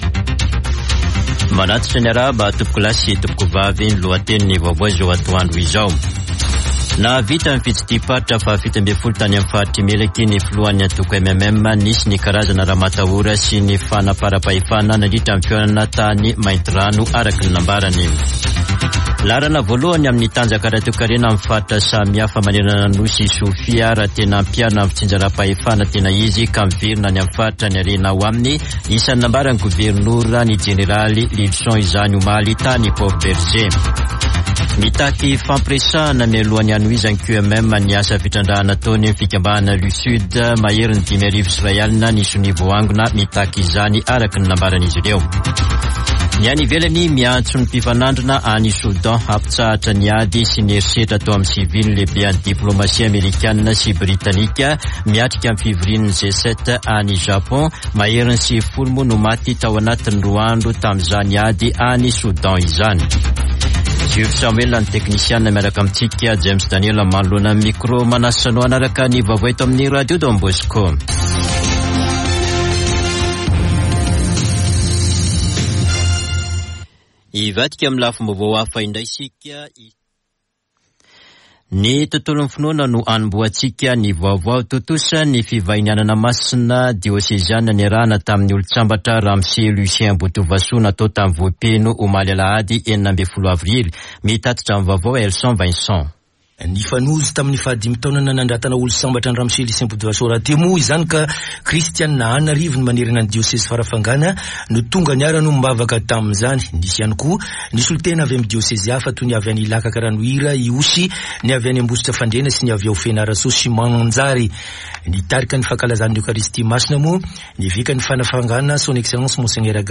[Vaovao antoandro] Alatsinainy 17 avrily 2023